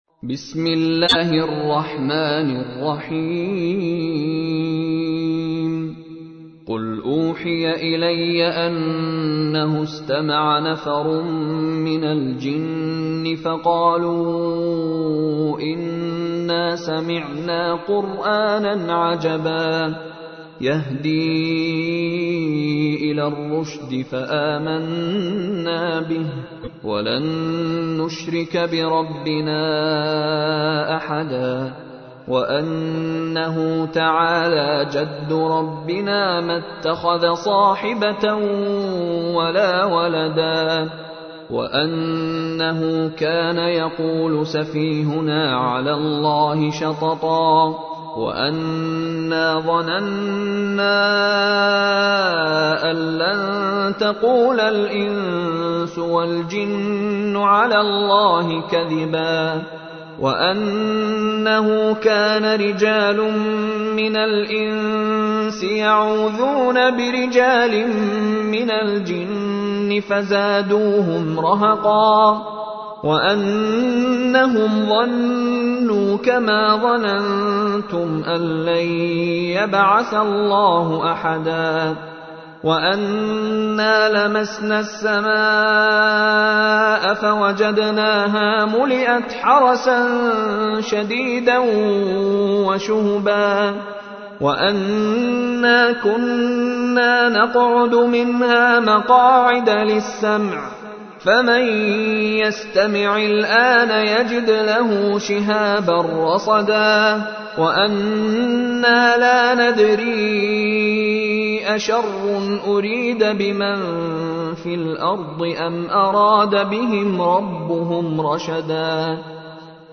تحميل : 72. سورة الجن / القارئ مشاري راشد العفاسي / القرآن الكريم / موقع يا حسين